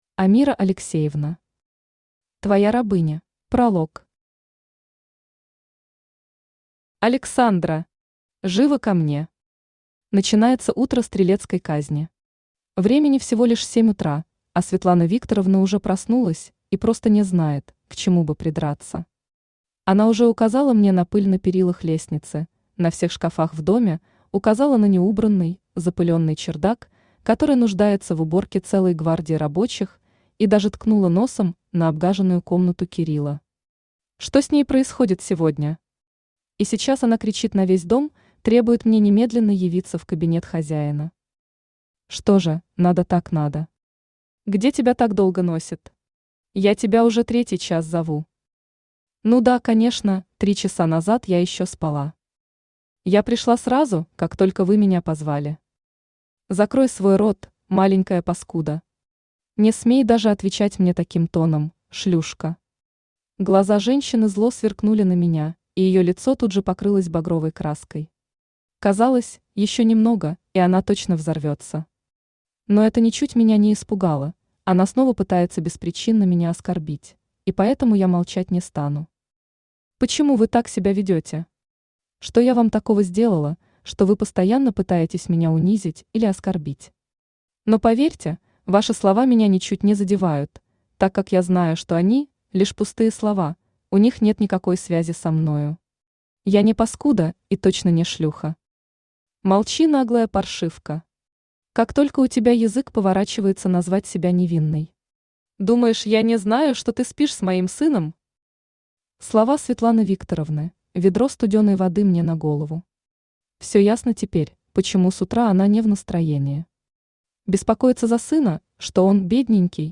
Аудиокнига Твоя рабыня | Библиотека аудиокниг
Aудиокнига Твоя рабыня Автор Амира Алексеевна Читает аудиокнигу Авточтец ЛитРес.